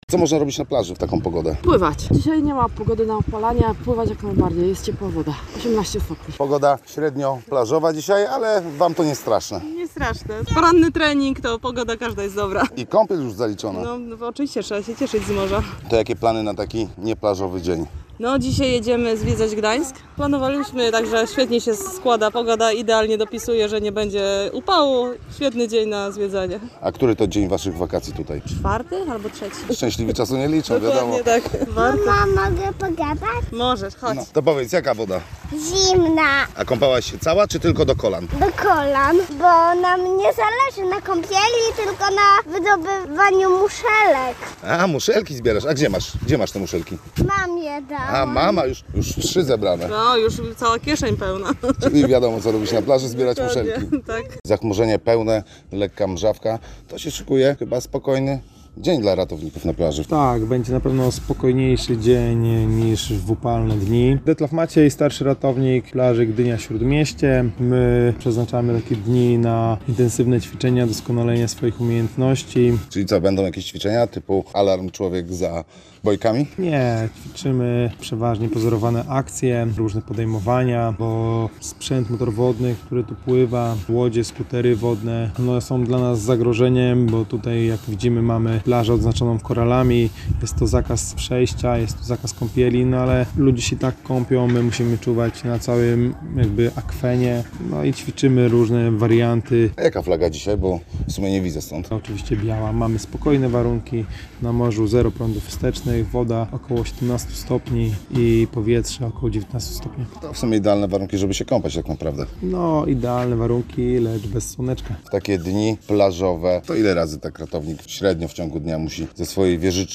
Nasz reporter rozmawiał w środowy poranek z plażowiczami w Gdyni.